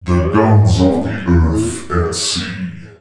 043 male.wav